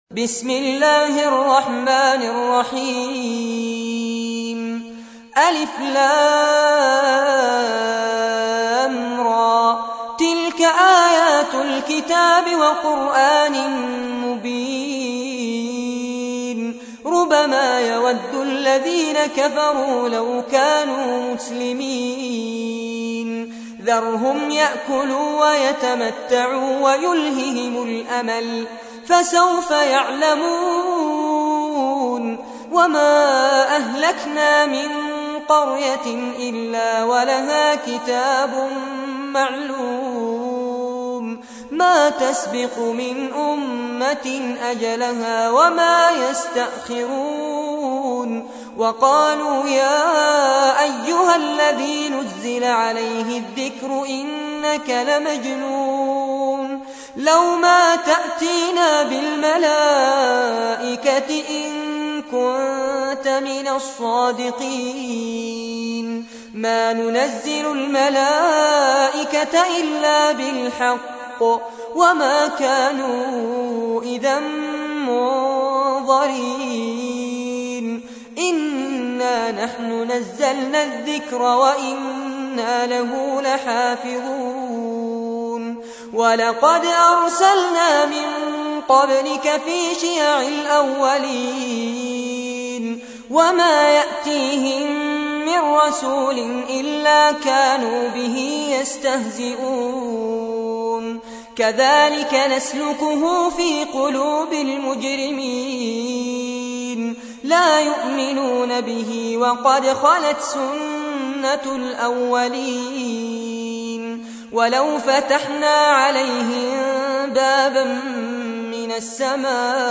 تحميل : 15. سورة الحجر / القارئ فارس عباد / القرآن الكريم / موقع يا حسين